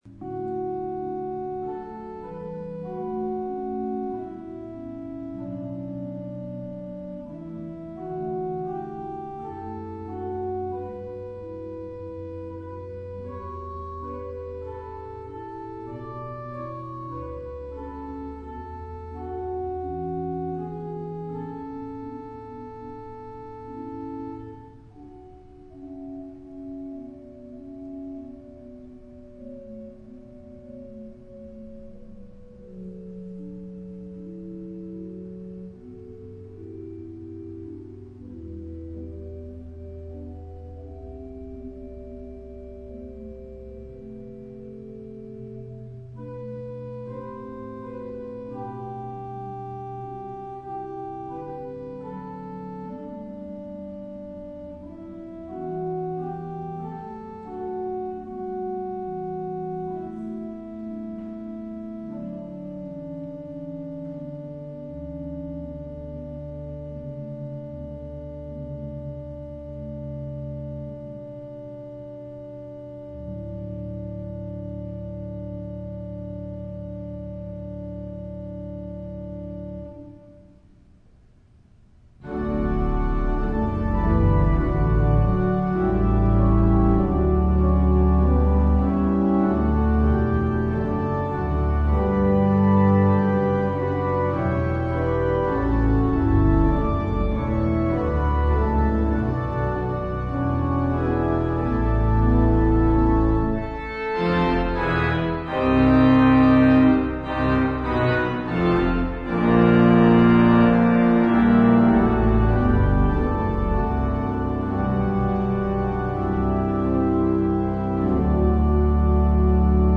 Voicing: Congregation, Solo Voice, Descant